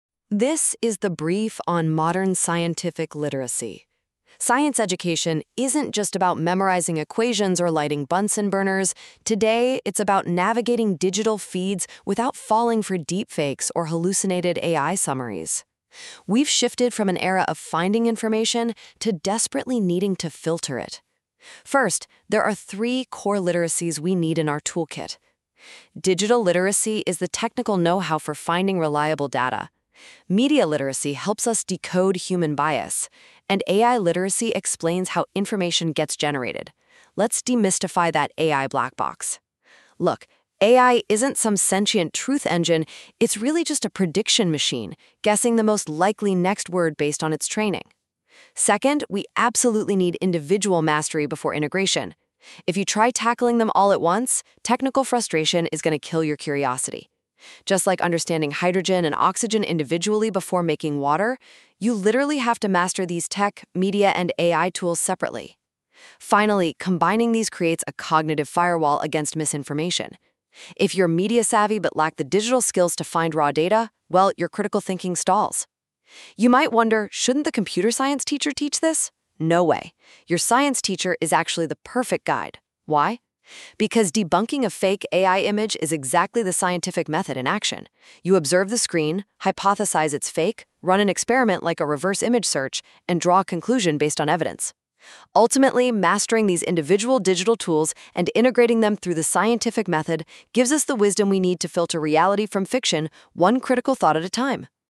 The following video and audio synopsis of this blog were generated using Google NotebookLM's features.